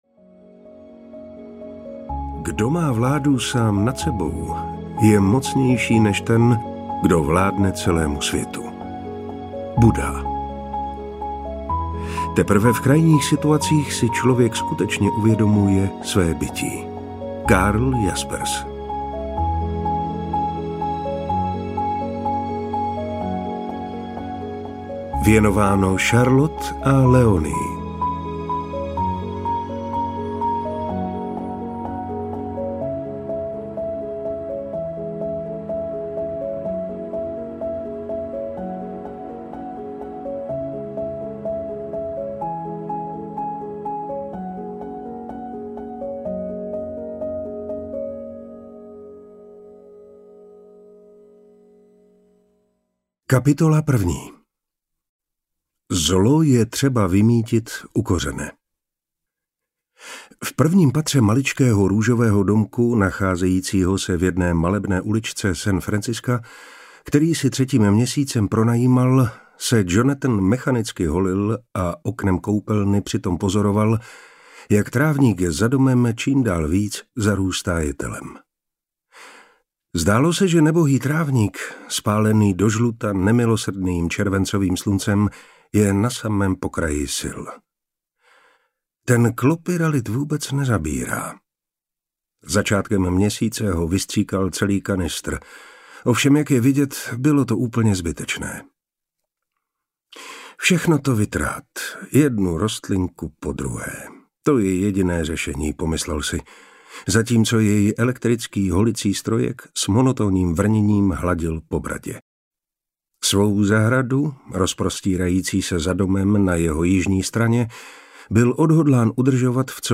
Den, kdy jsem se naučil žít audiokniha
Ukázka z knihy
• InterpretMartin Preiss